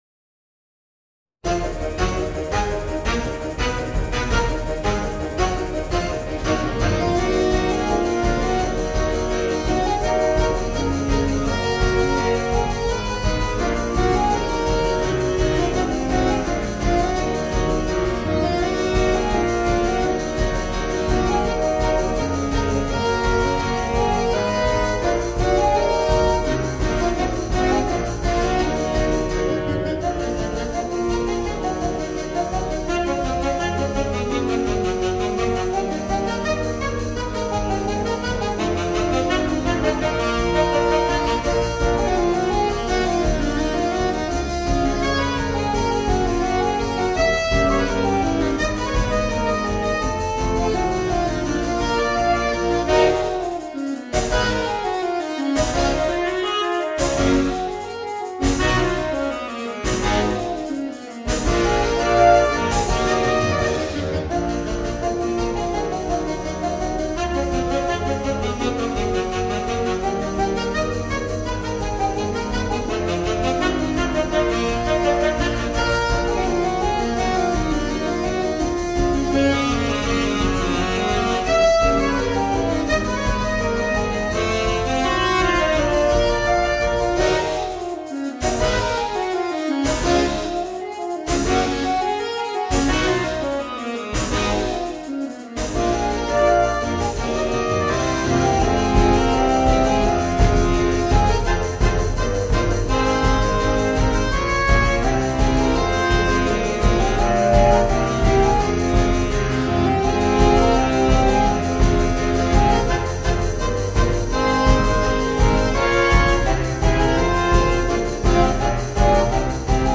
Voicing: 5 Saxophones